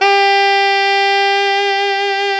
SAX ALTOFF0B.wav